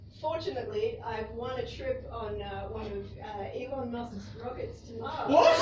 Category: Comedians   Right: Personal
Tags: rap